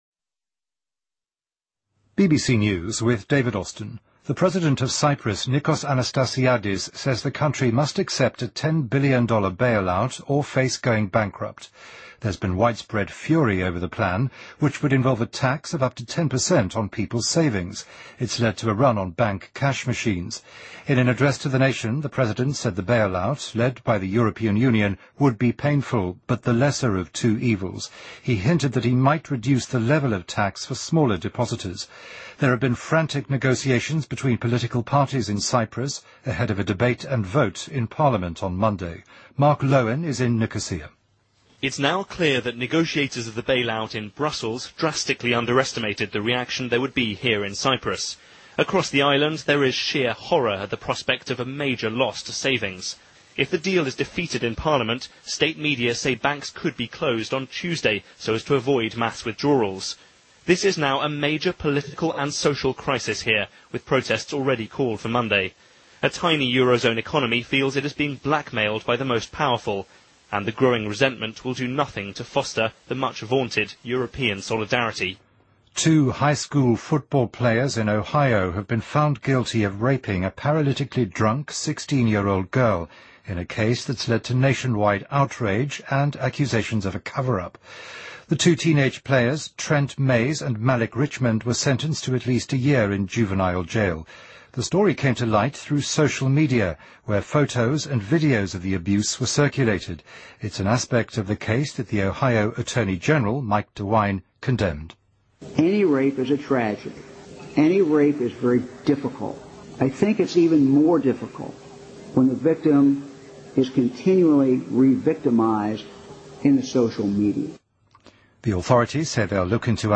BBC news,2013-03-18